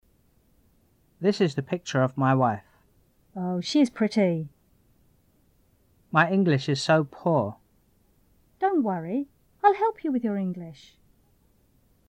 语音语调-陈述句-安慰、鼓励、友好 听力文件下载—在线英语听力室